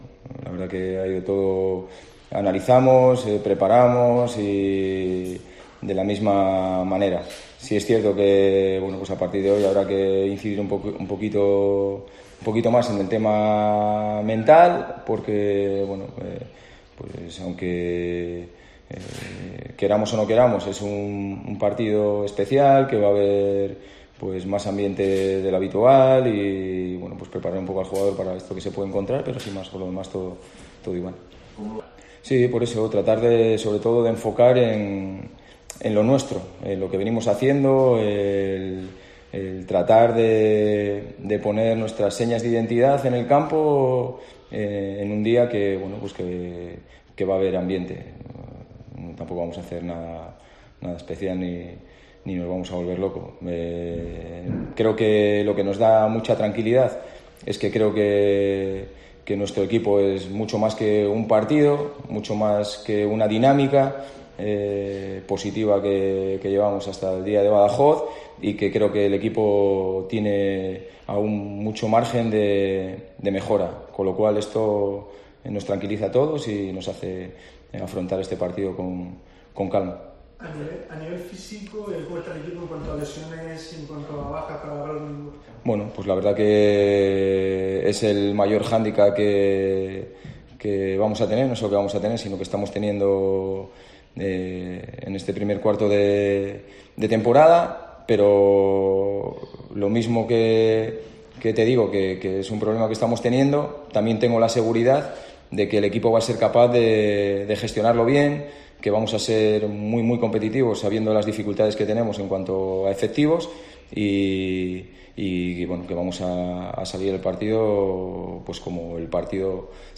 Pedro Munitis ha atendido hoy a los medios de comunicación en la sala de prensa de El Mayayo para analizar lo que será el derbi que se va a vivir el próximo domingo en La Condomina frente al Real Murcia.